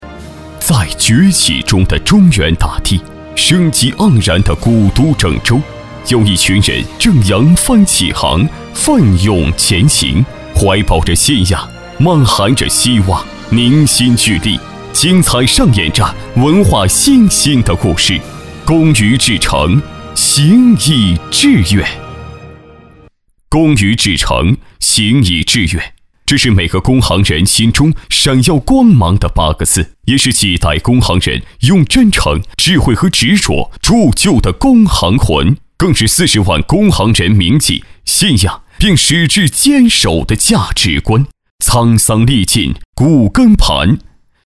稳重磁性 企业专题,人物专题,医疗专题,学校专题,产品解说,警示教育,规划总结配音
大气磁性男中音，豪迈激情。